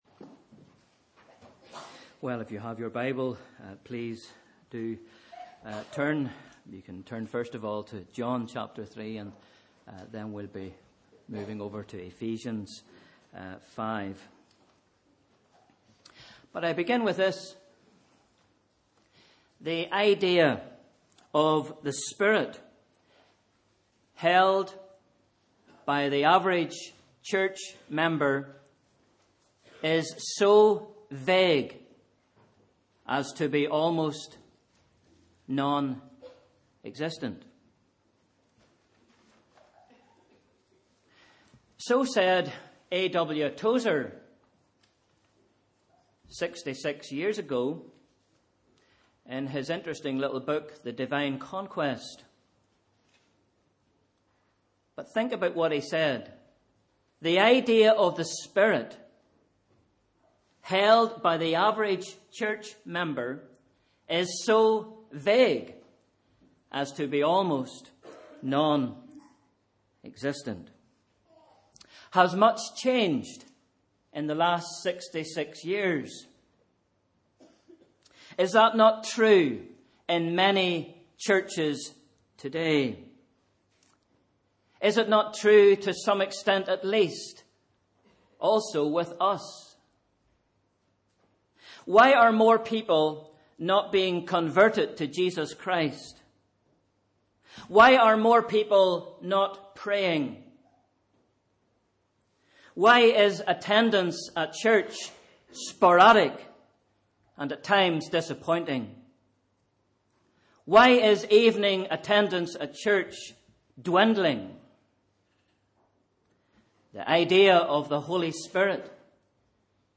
Sunday 10th January 2016 – Morning Service